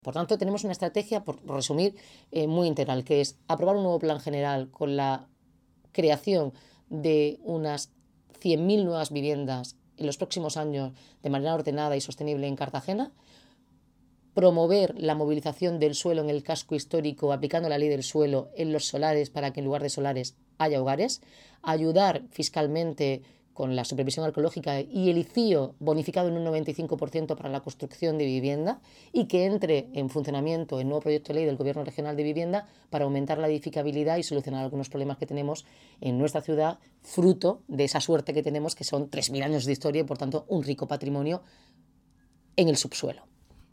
Audio: Declaraciones de la alcaldesa, Noelia Arroyo.